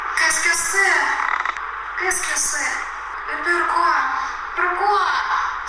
Je to z jednoho starého filmu.
Teda pustila jsem si jen první dvě věty, kde se holka ptá, co to má být a proč...
Ono víc než dvě věty v nahrávce ani není.